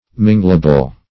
Mingleable \Min"gle*a*ble\